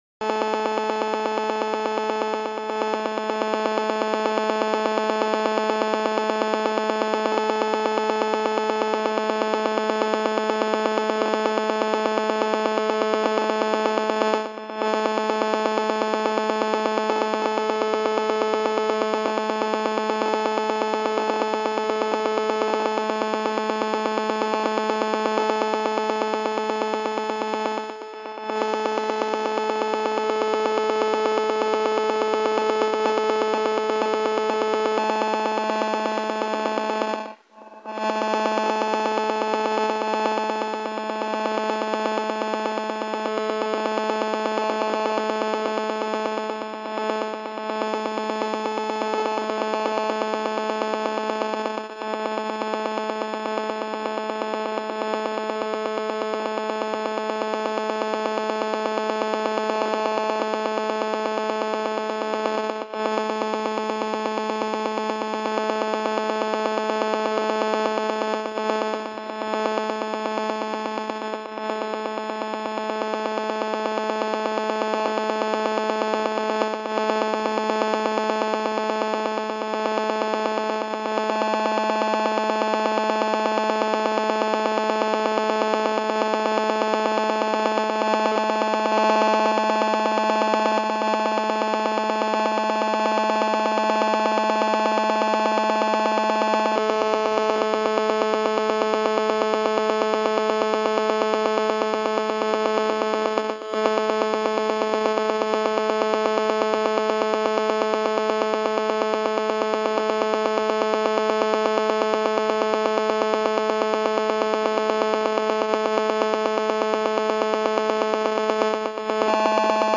File:GSM1800(DCS DL)-42888.mp3 - Signal Identification Wiki